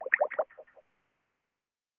Скайп звуки скачать, слушать онлайн ✔в хорошем качестве